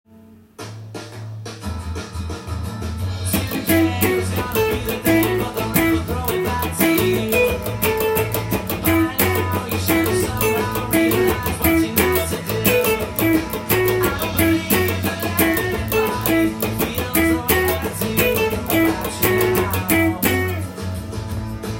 この曲のkeyはF♯ｍになります。
音源にあわせてカッティングしてみました